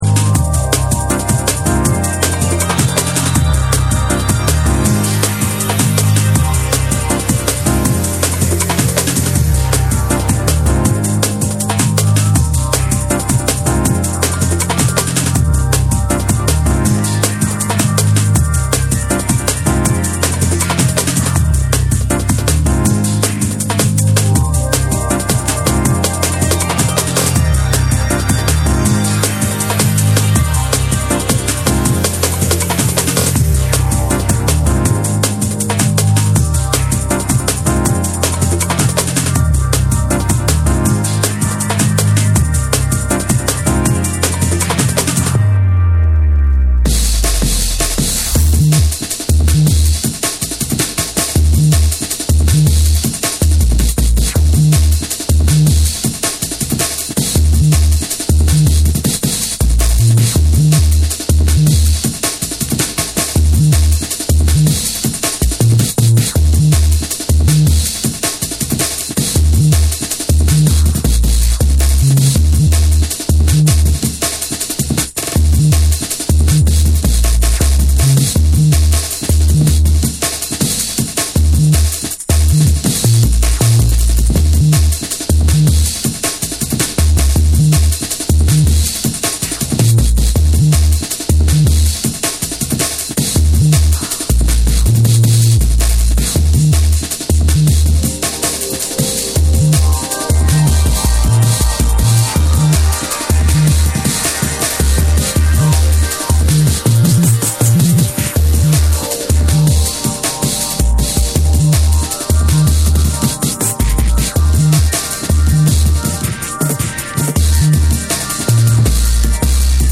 BREAKBEATS / JUNGLE & DRUM'N BASS